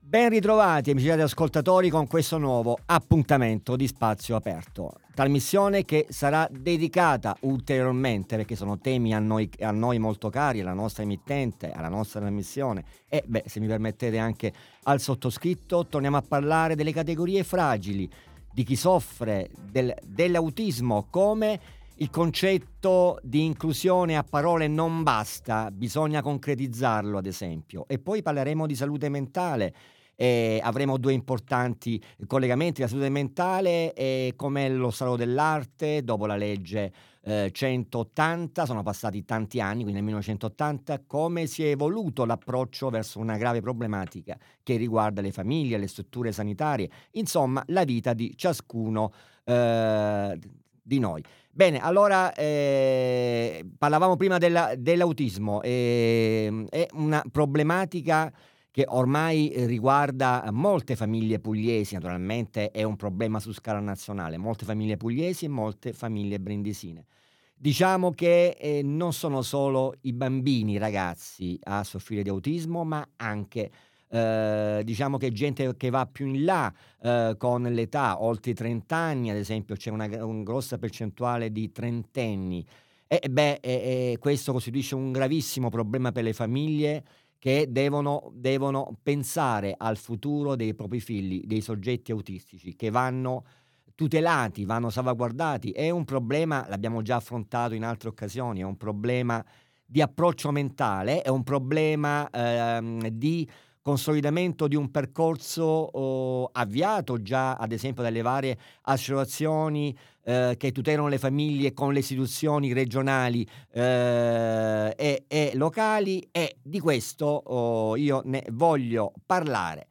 Diretta
Interviste